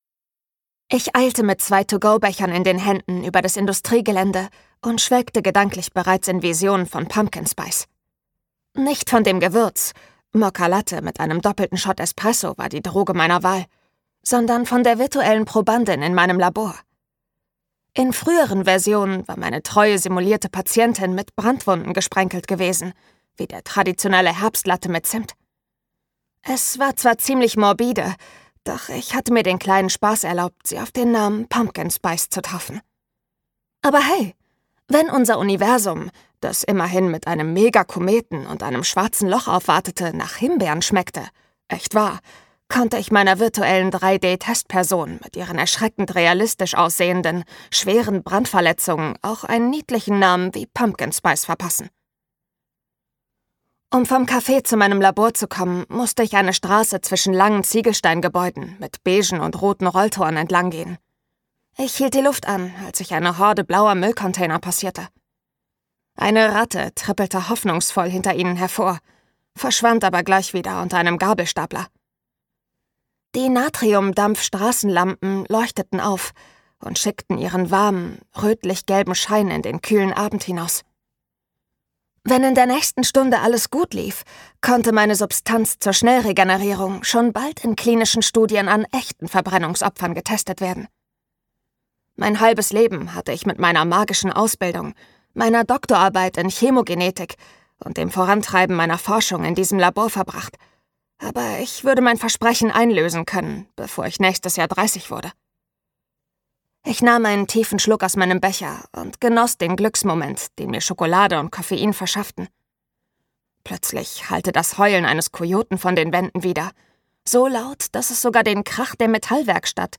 Vollmond für Anfänger - Deborah Wilde | argon hörbuch
Gekürzt Autorisierte, d.h. von Autor:innen und / oder Verlagen freigegebene, bearbeitete Fassung.